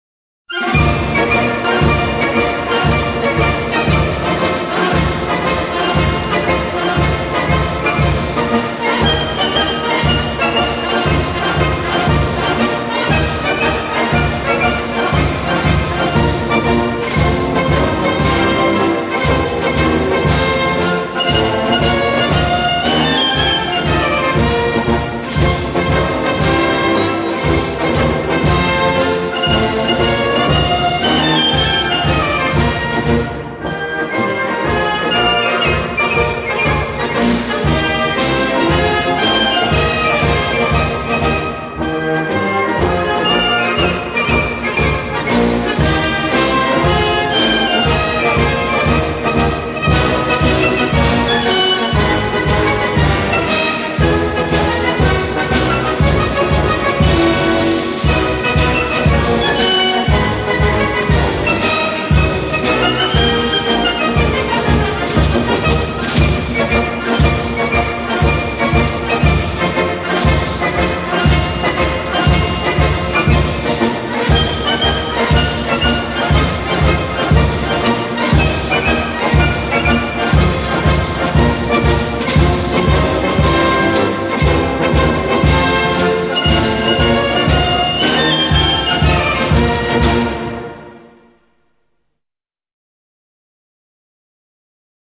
ACORDEON - ÓRGÃO
Folclore Austríaco/Tirolês